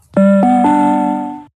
Sydney Trains Announcement Sound Button - Free Download & Play